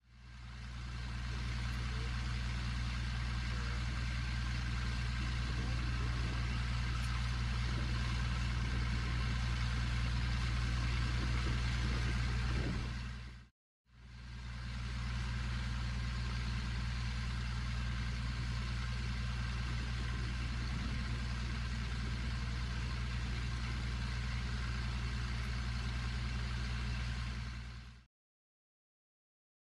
Burrowing Owl  MOV  MP4  M4ViPOD  WMV